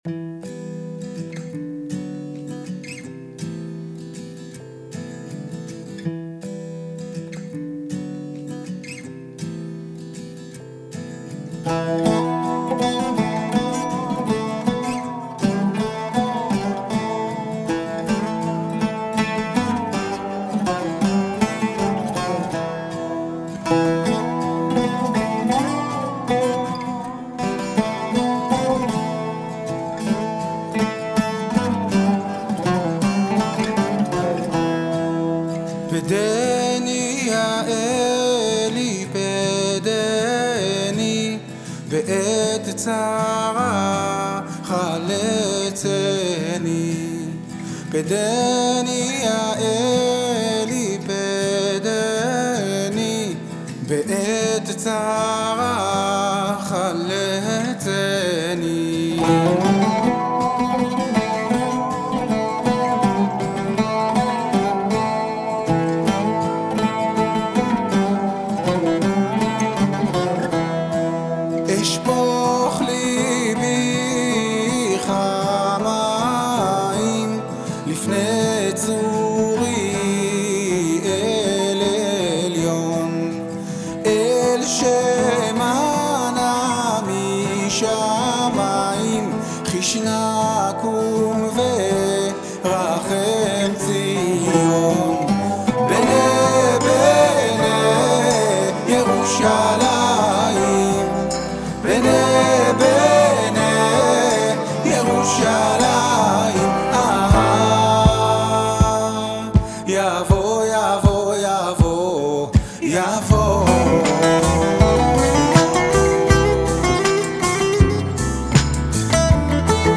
עוצר להפוגת זיכרון ומקליט פיוט תוניסאי לזכר אביו.